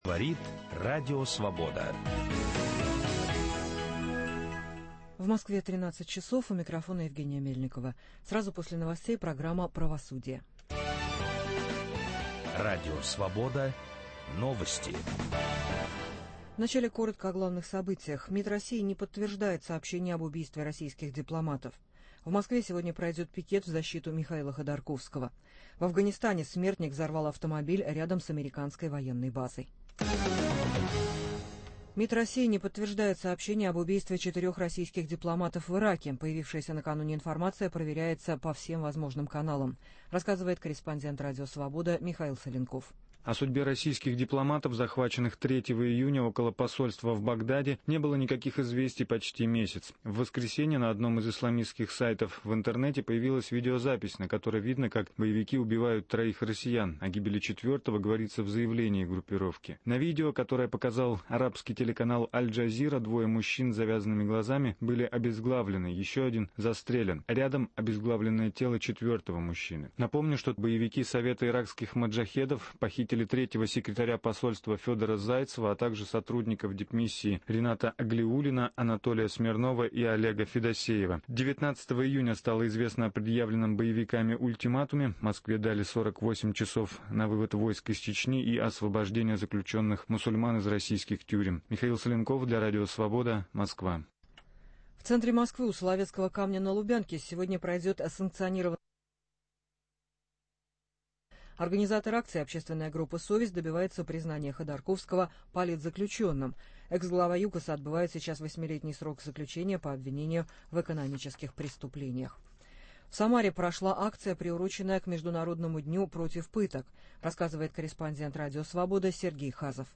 Эксперты в студии